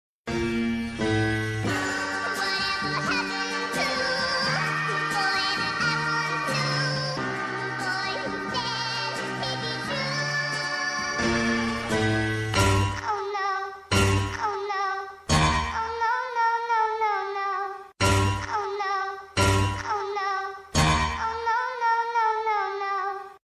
музыка которую включают когда произошло что-то ужасное